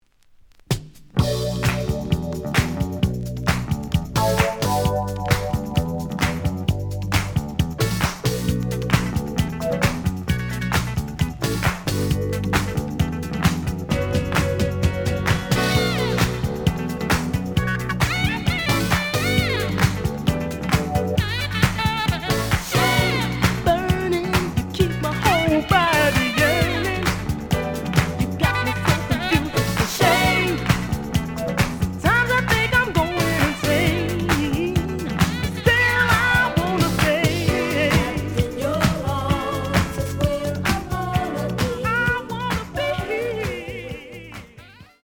The audio sample is recorded from the actual item.
●Genre: Disco
Edge warp.